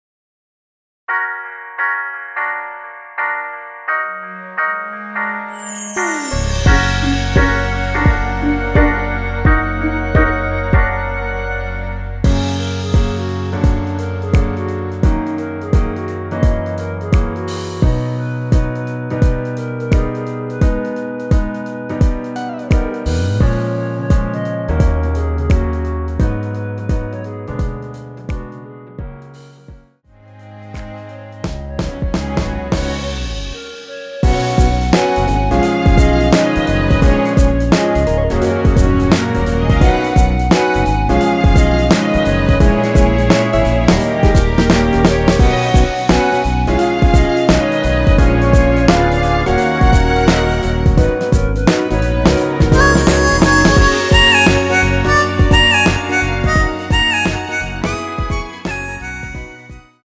엔딩이 페이드 아웃이라서 노래하기 편하게 엔딩을 만들어 놓았으니 코러스 MR 미리듣기 확인하여주세요!
원키에서(-2)내린 멜로디 포함된 MR입니다.
Gb
앞부분30초, 뒷부분30초씩 편집해서 올려 드리고 있습니다.